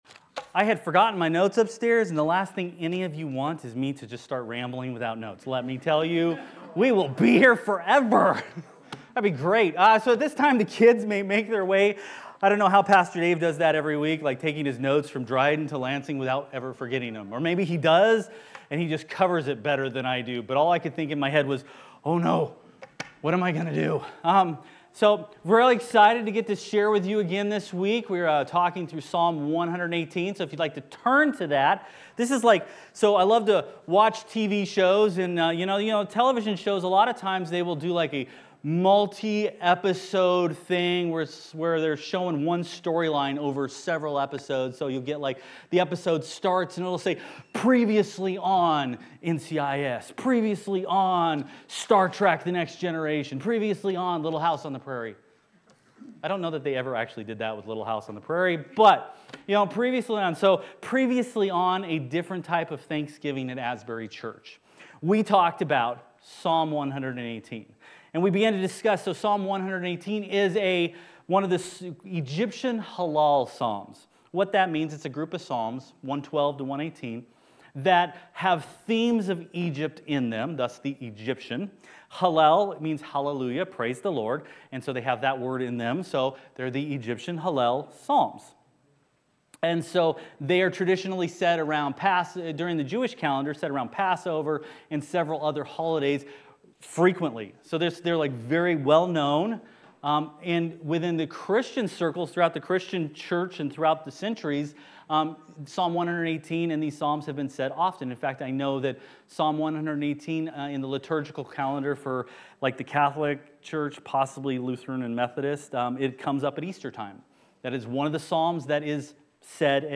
Passage: Psalm 118 Service Type: Main Service